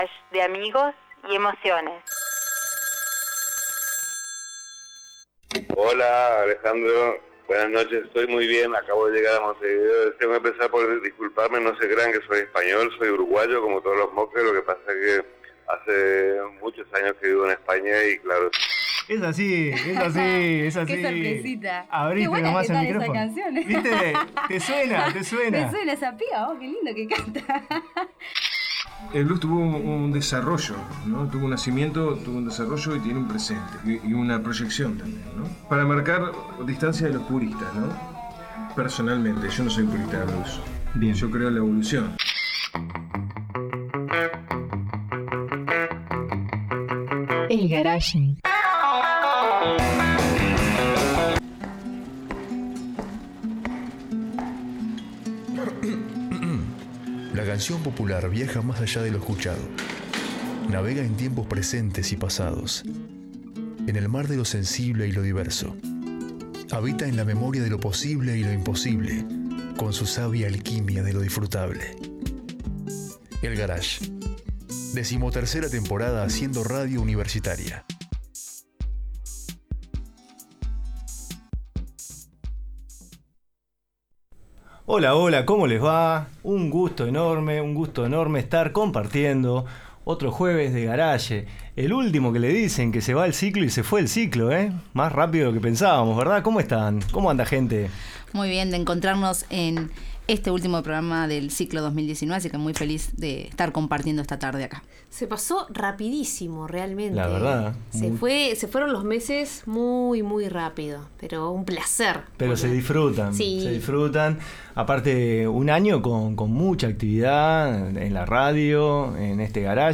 Como cierre del ciclo se recibió en estudio a los músicos